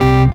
H11SYNTH.wav